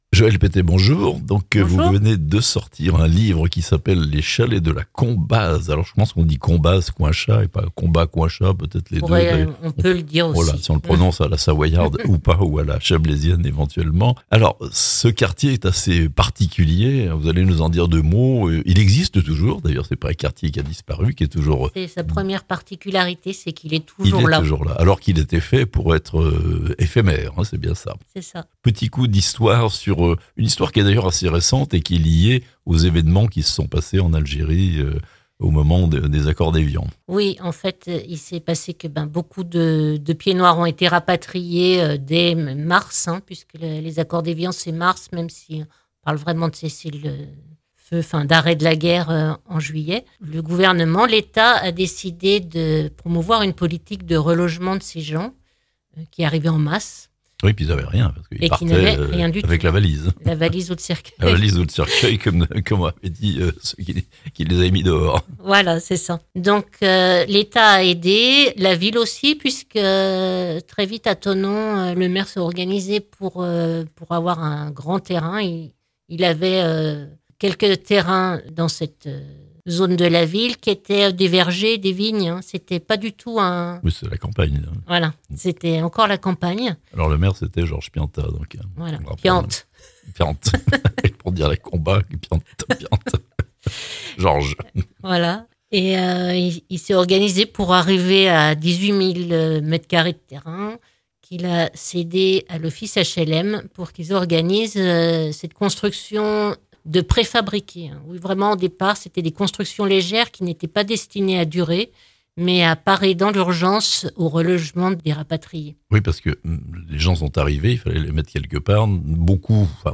Un quartier singulier de Thonon, La Combaz Coinchat (interview)